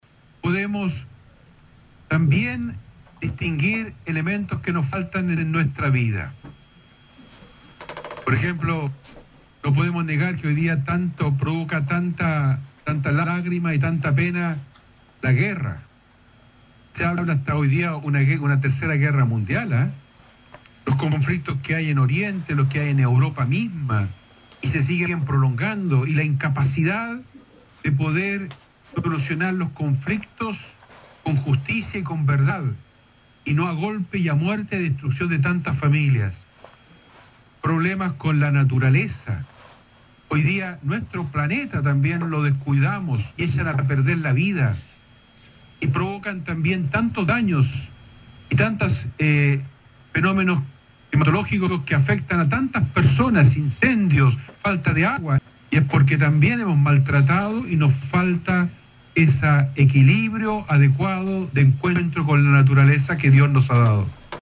En la isla de la devoción encabezó la jornada repleta de fervor religioso el Obispo de la Diócesis, Monseñor Juan María Agurto, quien en su homilía hizo referencia al cuidado que debemos tener con el medio ambiente y la naturaleza.